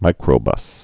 (mīkrō-bŭs)